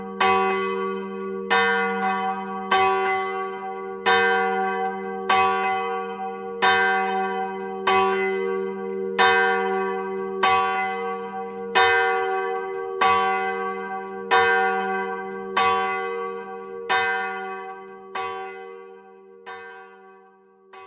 Ook de klokken van Heel hebben door de samenstelling van het materiaal een warme klankkleur en een lange uitklinktijd (nagalm).
De kleine klok
De klank is hoger dan die van de grotere en zwaardere Mariaklok .
Luister naar het luiden van de kleine klok.
Kerkklok-Heel-Kleine-klok-Steven.mp3